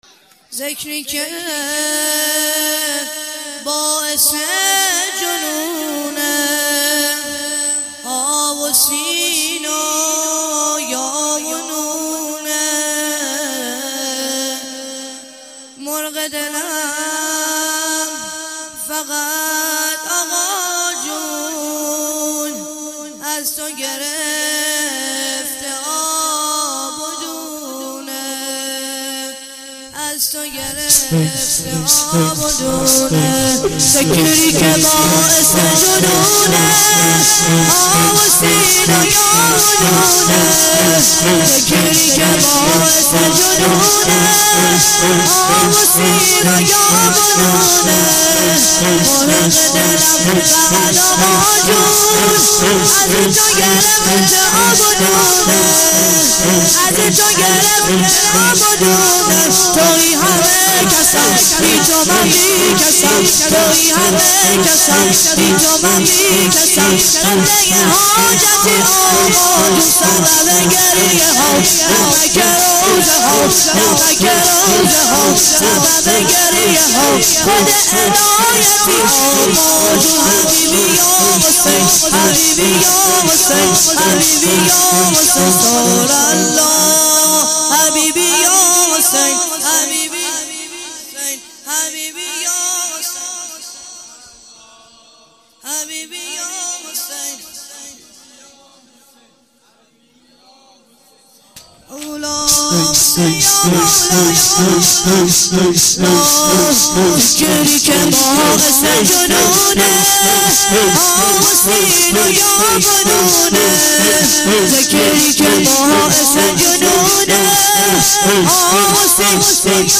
شور - ذکری که باعث جنونه ح س ی ن - مداح